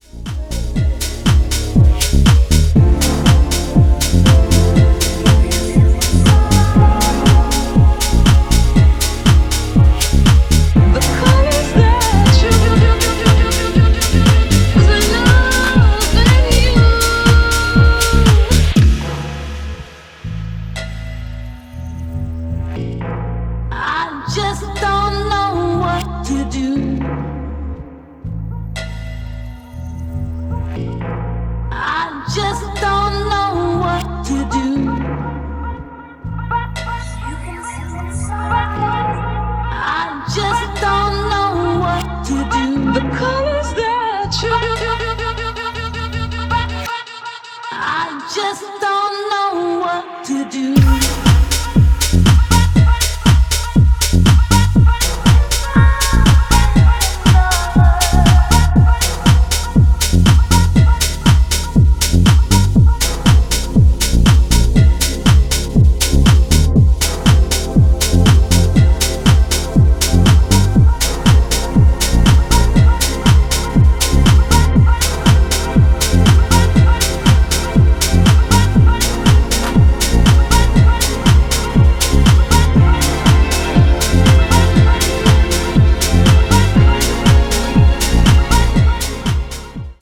メランコリックなムードも手伝い匿名性の高い内容に仕上がっていて、シークレット・ウェポンとして機能してくれそうです！